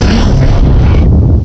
cry_not_landorus.aif